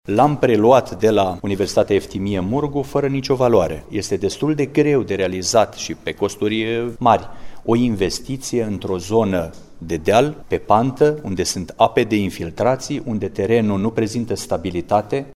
Mihai Stepanescu explică de ce s-a renunţat la refacerea teatrului: